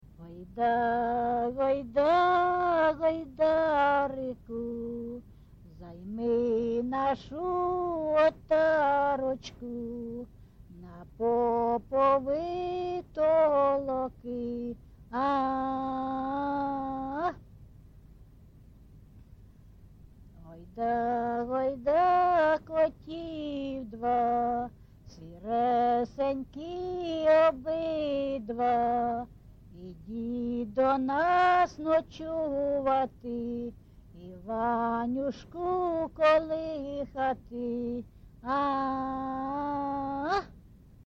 ЖанрКолискові
Місце записус. Андріївка, Великоновосілківський район, Донецька обл., Україна, Слобожанщина